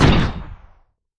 Human_03_Atk.wav